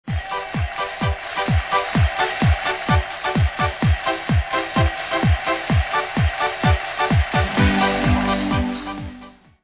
weekday morning programmes are fronted by this ident